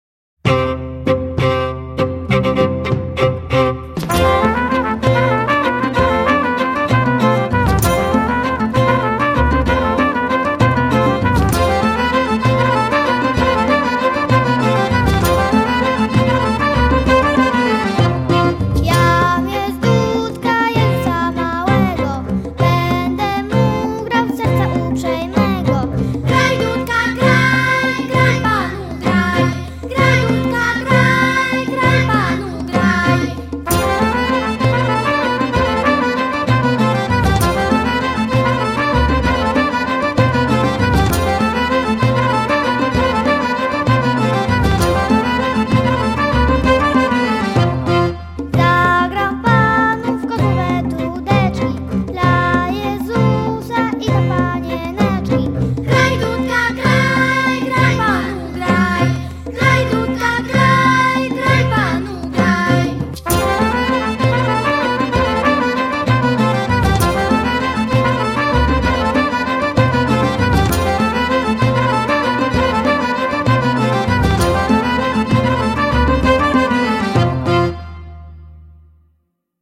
Jam jest dudka (wersja wokalno-instrumentalna)